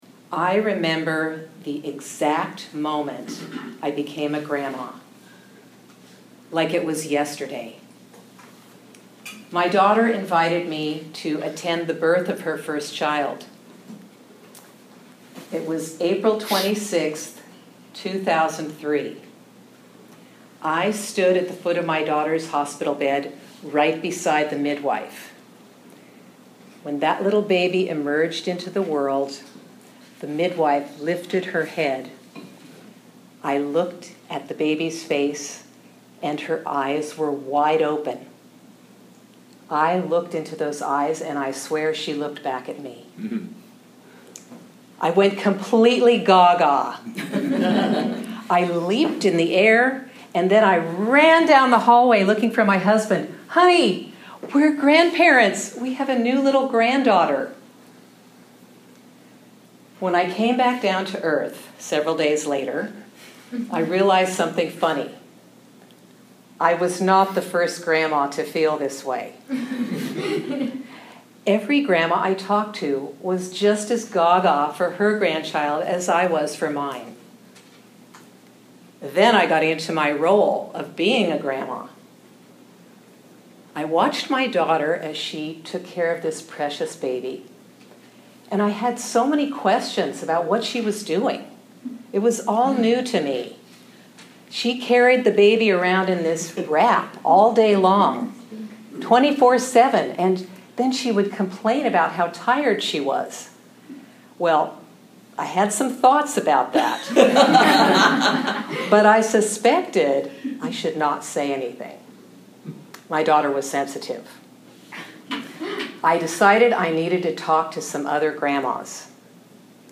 Below is a 9-minute version of my one-hour presentation.